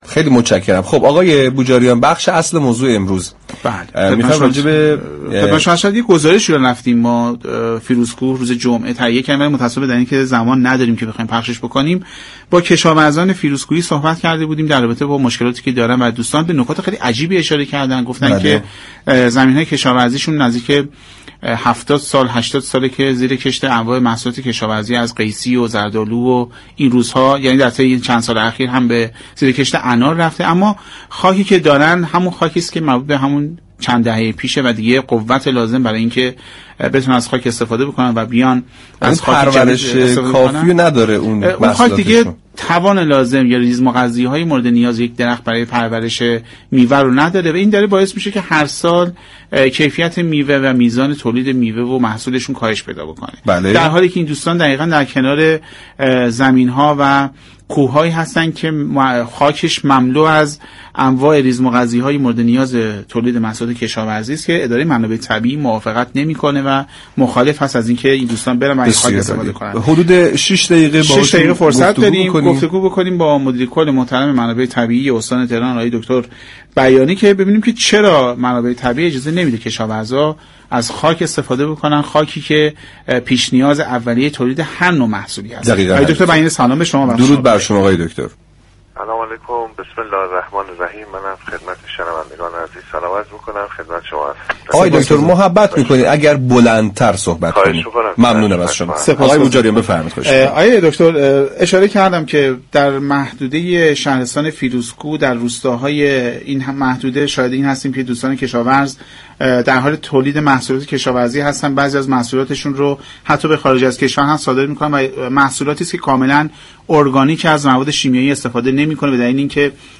به گزارش پایگاه اطلاع رساتنی رادیو تهران، برخی باغداران فیروزكوه اظهار می كنند خاك باغ های آنها كه از سالها پیش محصولات خود را در آن پرورش می دهند دیگر قوت سال های گذشته را ندارد تا همچنان موجب پرورش میوه هایی با كیفیت بالا شود به همین جهت كیفیت میوه های انها هر سال كاهش می یابد در همین راستا برنامه سعادت آباد 14 آبان با دكتر بیانی مدیركل اداره منابع طبیعی و آبخیزداری استان تهران در خصوص اینكه چرا منابع طبیعی اجازه نمی‌دهد تا این باغداران از خاك كوه ها و مراتع محدوده فیروزكوه استفاده كنند؟؛ گفت‌و ‌گو كرد.